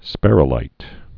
(spĕrĭ-līt)